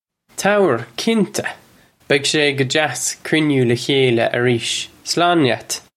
Pronunciation for how to say
Tower, kinncha. Beg shay guh jass krin-yoo luh khayla areesh. Slawn lyat!